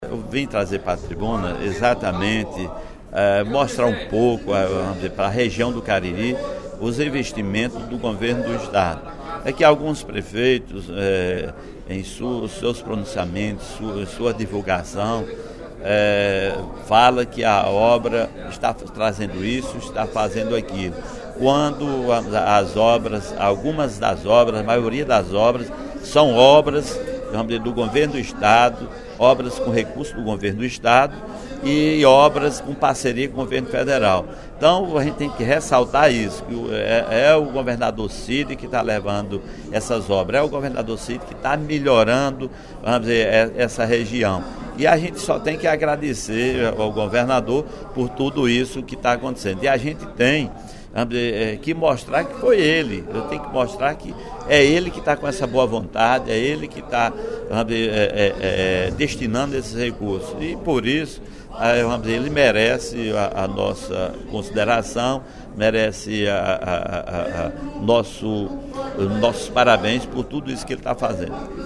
No primeiro expediente da sessão plenária desta quarta-feira (12/03), o deputado Sineval Roque (Pros) lamentou o que vem acontecendo no município do Crato em relação ao prefeito Samuel Araripe, mas ponderou que ficaria fora da discussão.